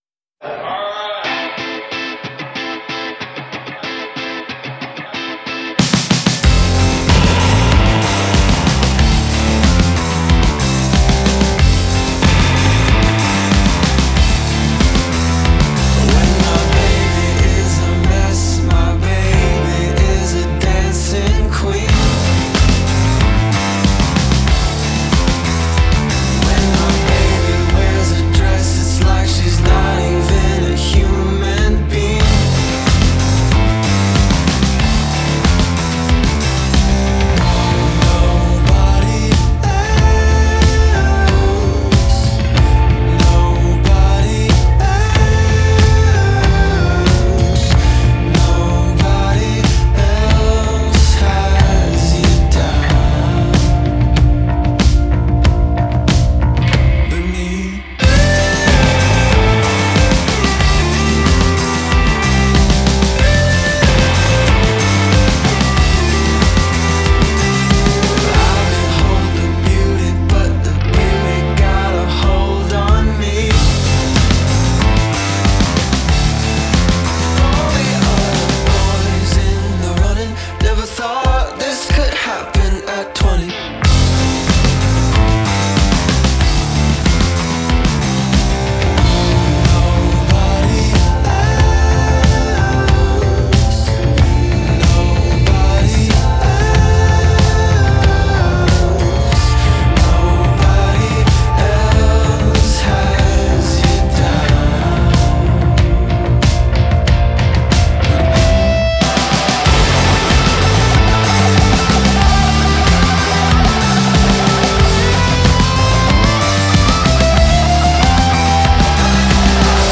Genre: Indie